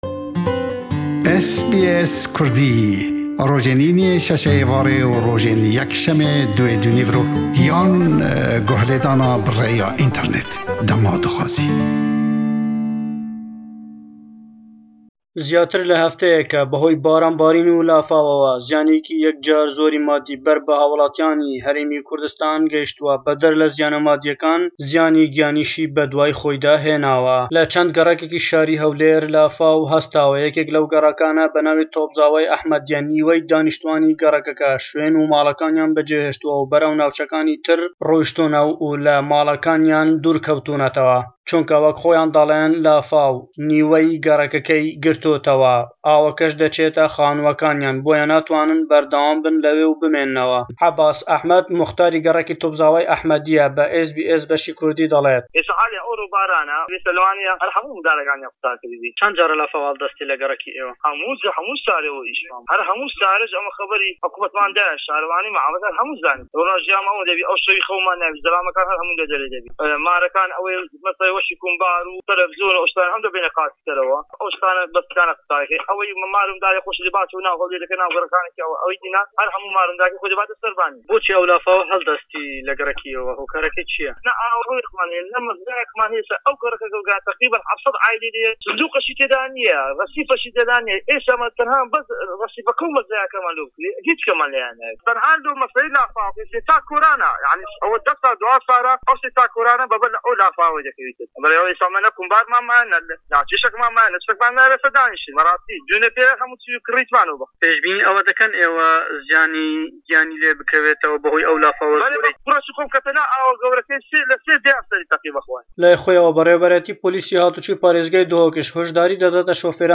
Baranî zor le 10 rojî rabirdû da le Herêmî Kurdistan zîyanî darayî û cesteyî lê kewtuwetewe. Raportî